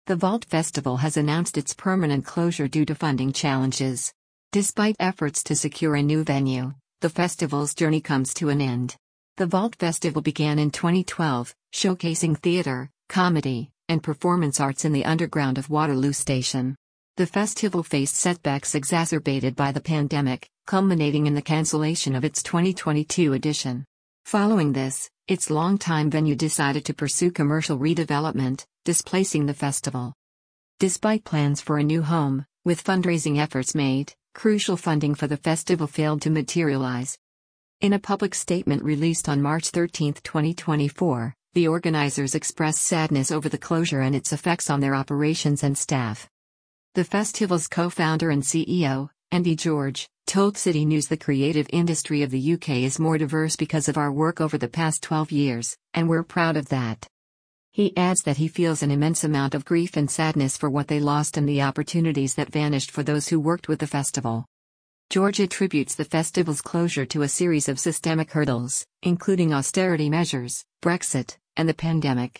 Listen to this article powered by AI.